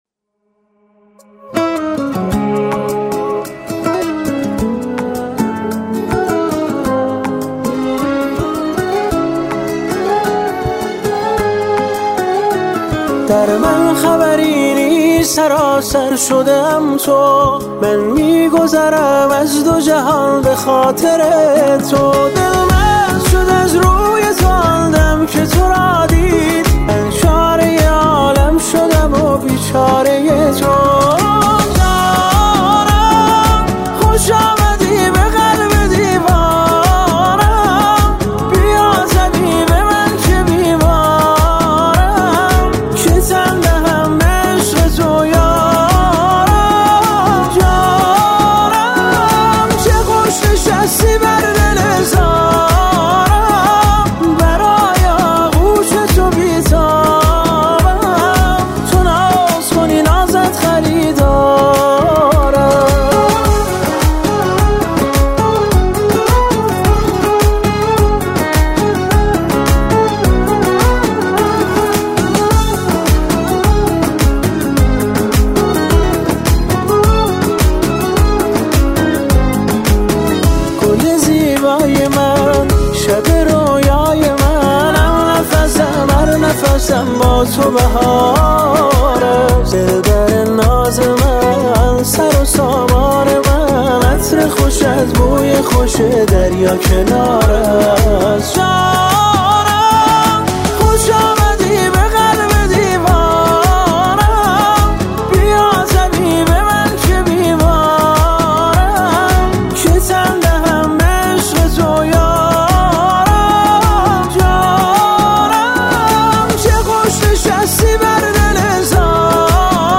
اهنگ احساسی